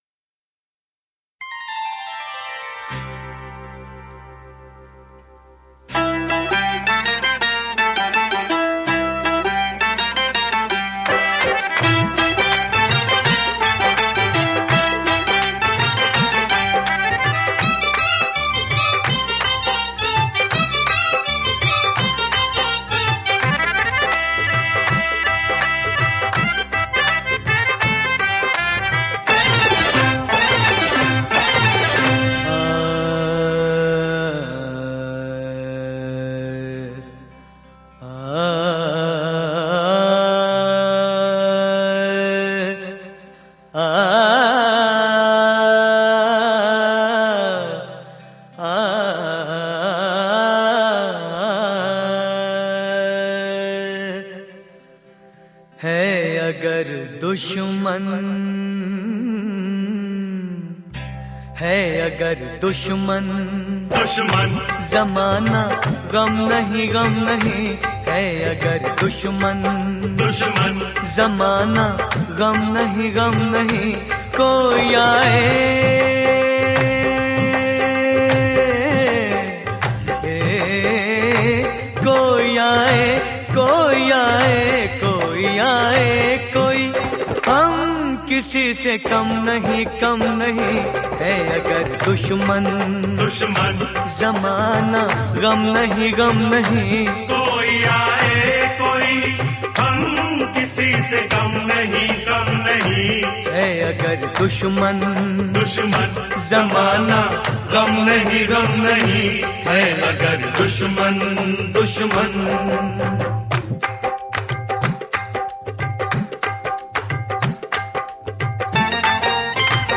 Urdu Qawwali MP3